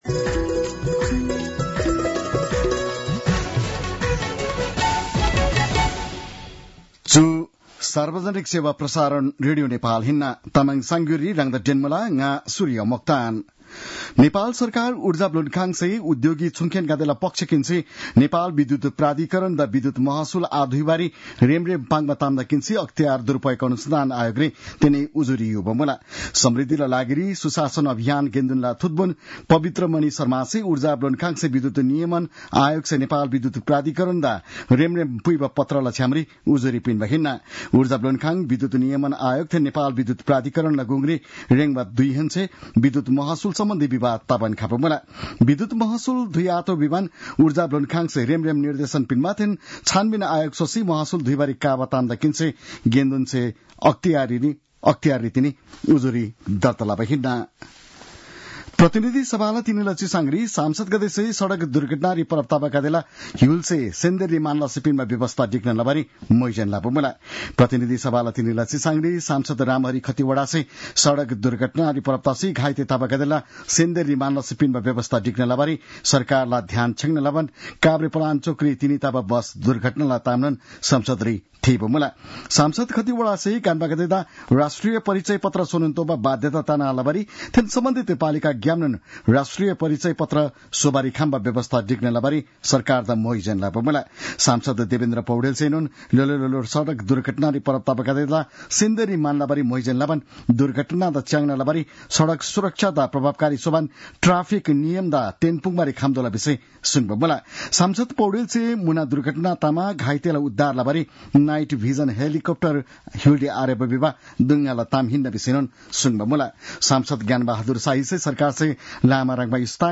An online outlet of Nepal's national radio broadcaster
तामाङ भाषाको समाचार : १४ फागुन , २०८१